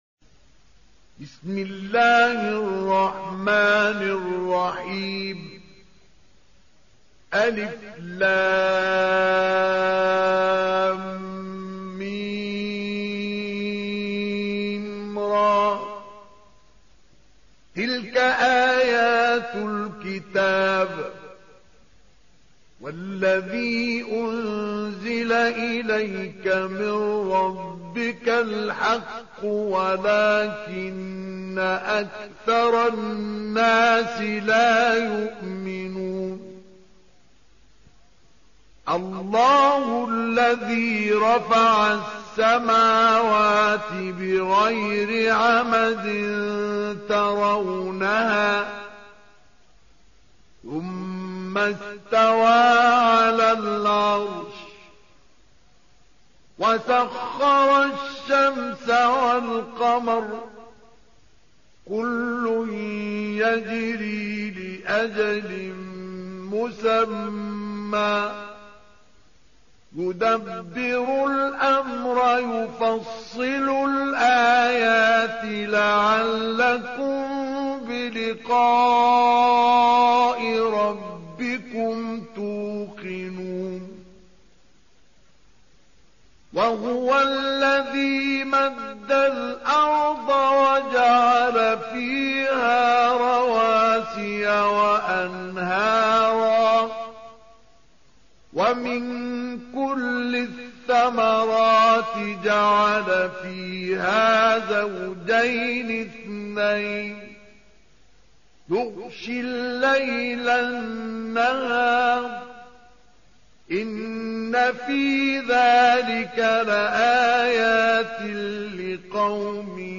13. Surah Ar-Ra'd سورة الرعد Audio Quran Tarteel Recitation
Surah Sequence تتابع السورة Download Surah حمّل السورة Reciting Murattalah Audio for 13. Surah Ar-Ra'd سورة الرعد N.B *Surah Includes Al-Basmalah Reciters Sequents تتابع التلاوات Reciters Repeats تكرار التلاوات